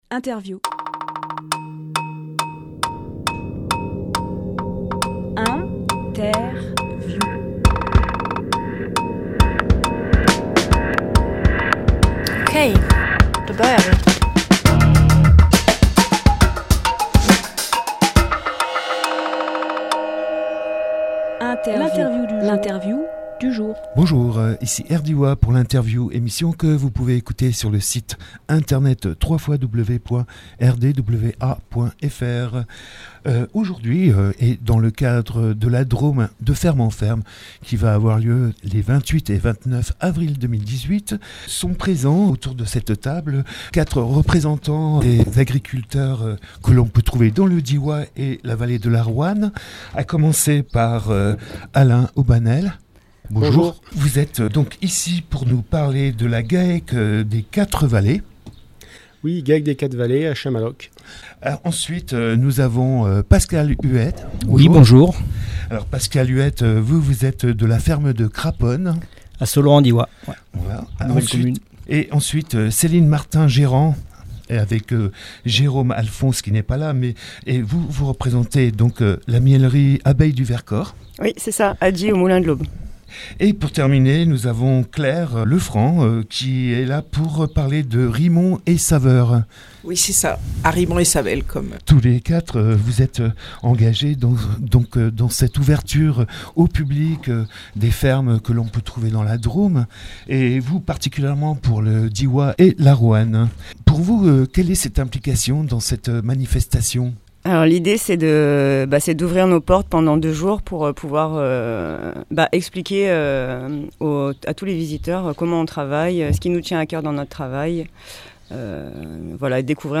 Emission - Interview De ferme en ferme Publié le 25 avril 2018 Partager sur…
Lieu : Studio RDWA